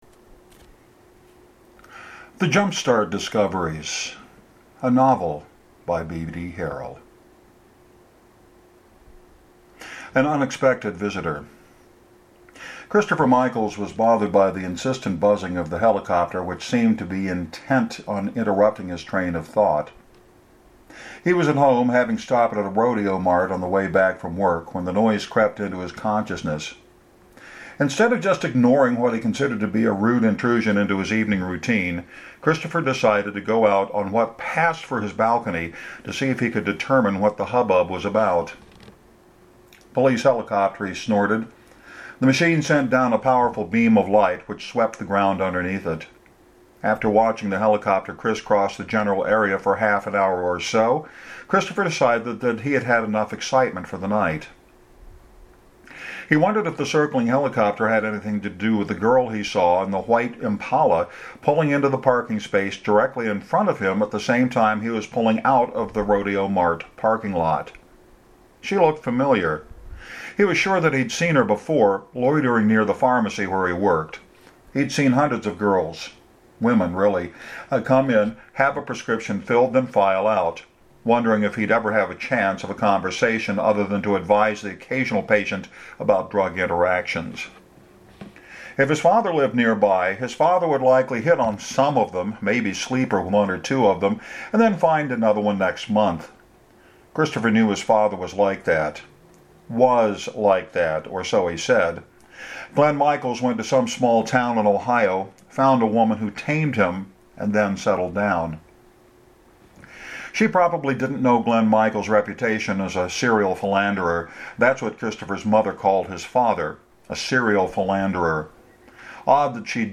Going back to the once weekly reading of chapters of works in progress.